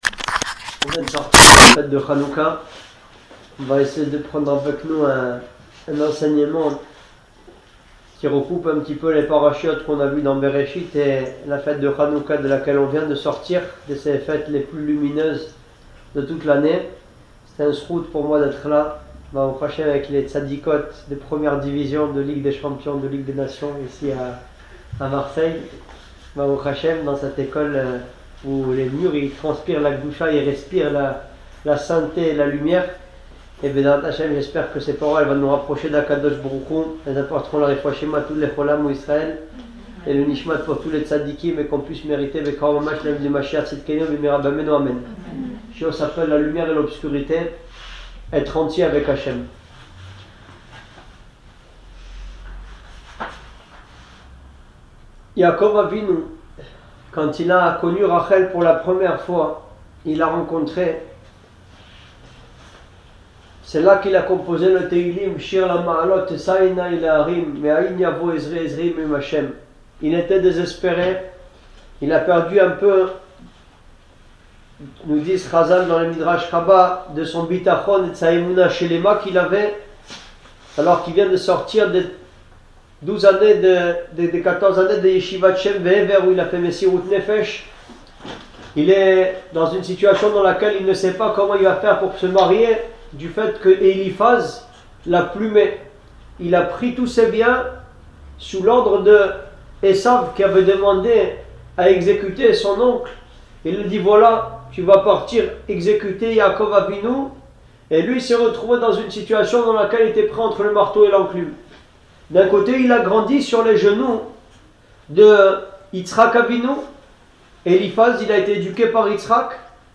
Shiour de la Tournée Hanouka 2018 : Lumière et obscurité – Etre entier avec Hachem
Cours